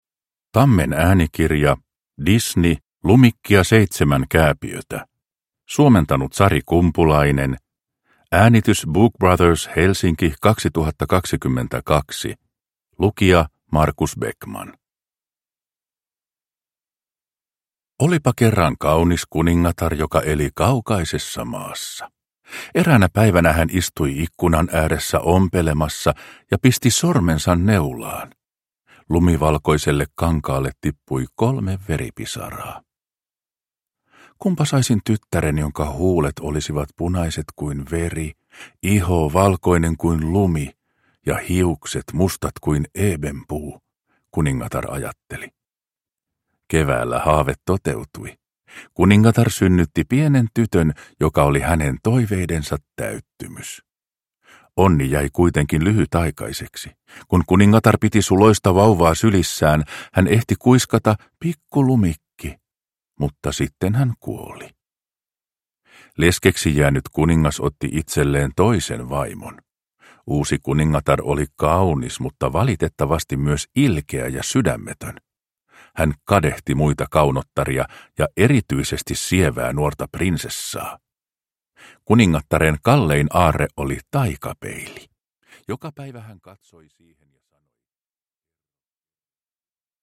Lumikki ja seitsemän kääpiötä – Ljudbok – Laddas ner